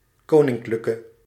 1. ^ Also sometimes rendered in its inflected form Koninklijke (pronounced [ˈkoːnɪŋkləkə]
Nl-koninklijke.ogg.mp3